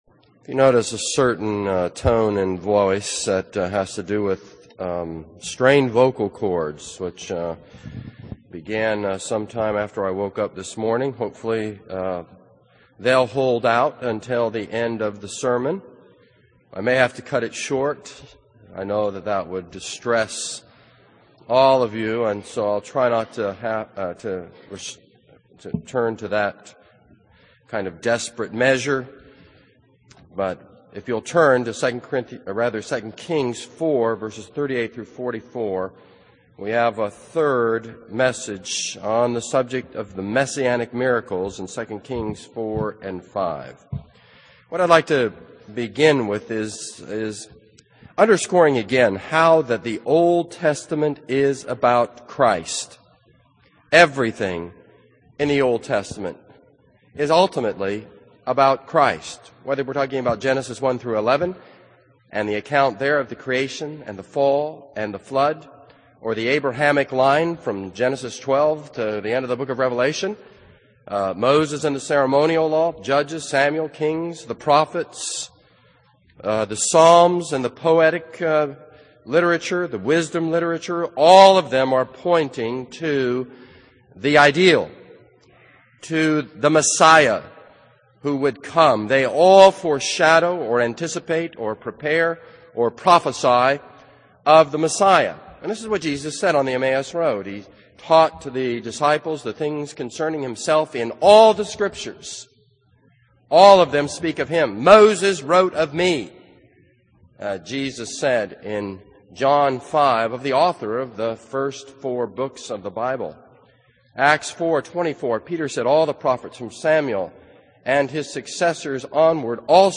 This is a sermon on 2 Kings 4:38-44.